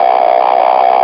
radar_track.wav